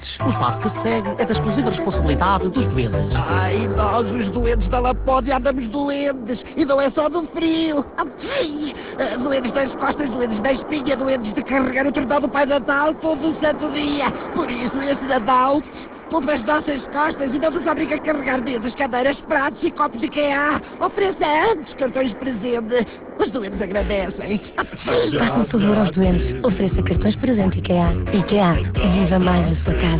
No passado dia 15 de Novembro, o IKEA, estreou a sua campanha de Natal em que aconselha a oferta de cartões presente IKEA. Esta campanha passa na RFM, RC e TSF e tem 4 spots diferentes (